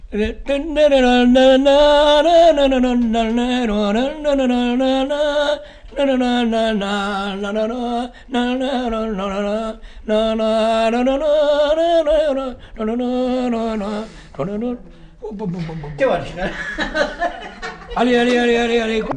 Aire culturelle : Agenais
Lieu : [sans lieu] ; Lot-et-Garonne
Genre : chant
Effectif : 1
Type de voix : voix d'homme
Production du son : fredonné
Danse : bourrée